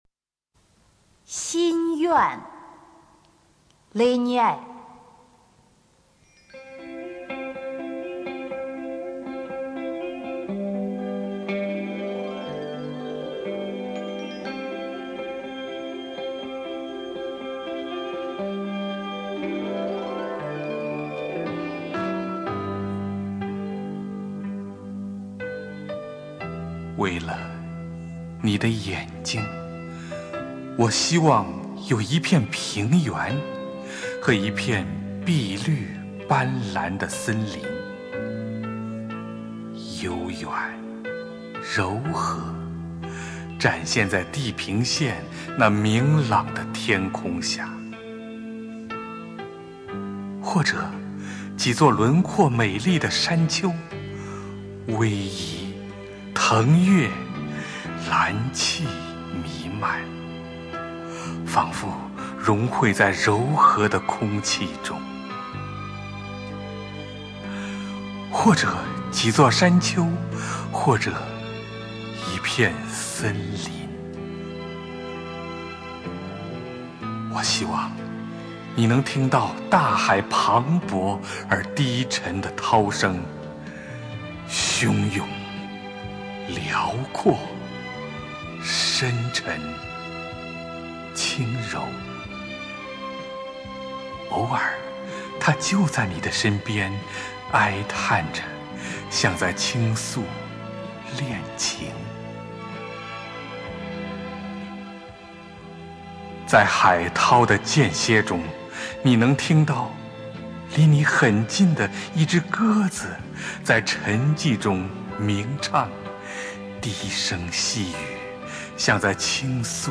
首页 视听 经典朗诵欣赏 丁建华、乔榛：外国爱情诗配乐朗诵